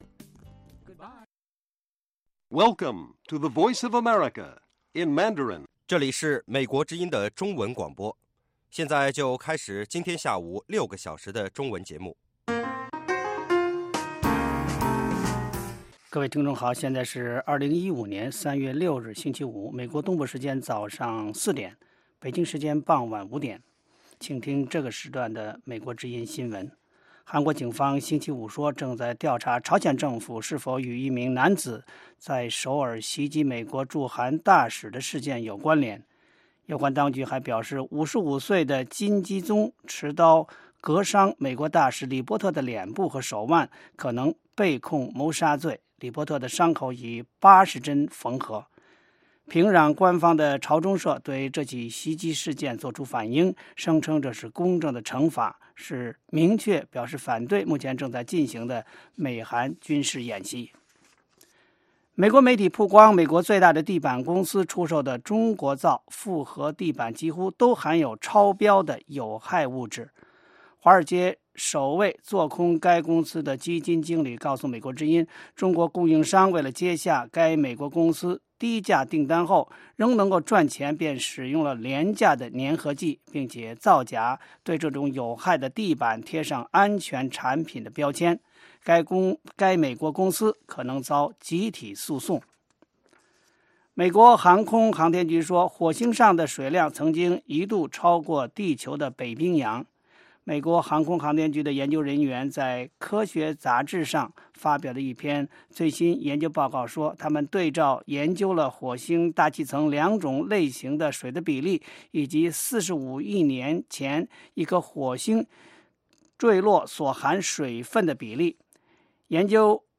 北京时间下午5-6点广播节目。 内容包括国际新闻和美语训练班（学个词，美国习惯用语，美语怎么说，英语三级跳，礼节美语以及体育美语）